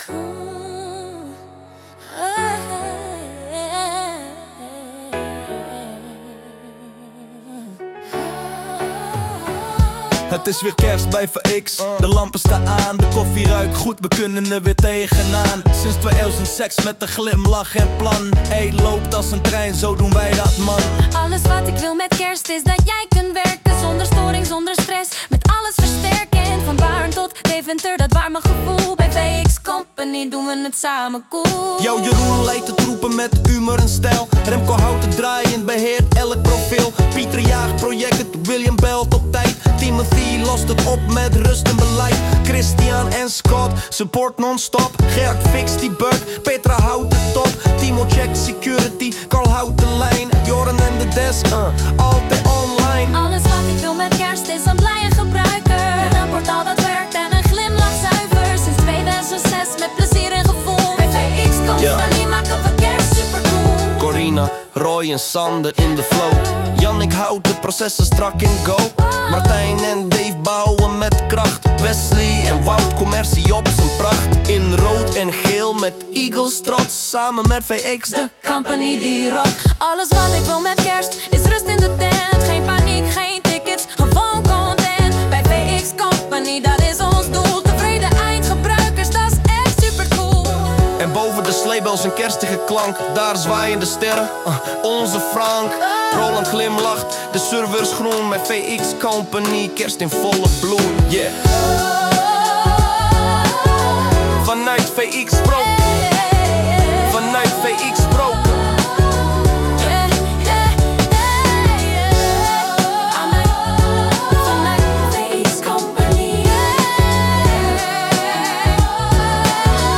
Inclusief refrein, rapstuk en een vleugje “foute kersttruien”-energie?
Wij combineerden een kerstvibe à la Mariah Carey met een modern rapmoment zoals Eminem. Suno zet dit in één minuut om naar een volledig gezongen en geproduceerde track.
Kerst bij VX – All I Want is Uptime 140 BPM.mp3